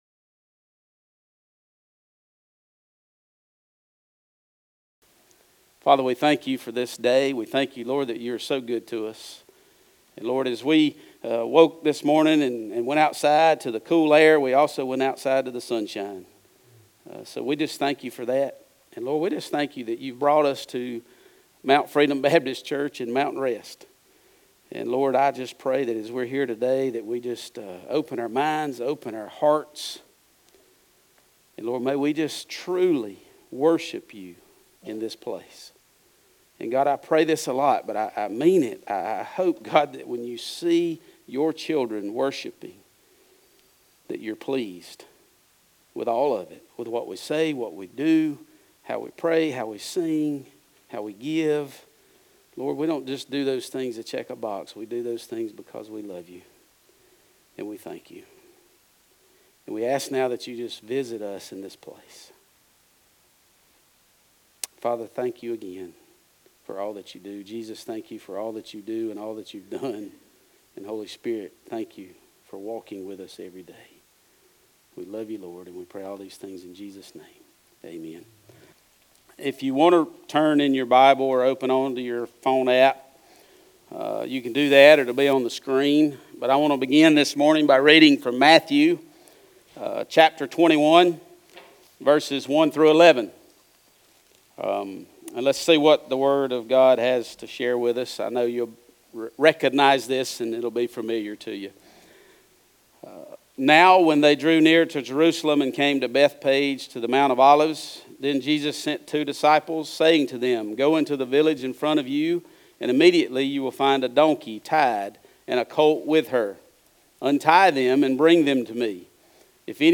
Message Type - Sermon
Occasion - Sunday Worship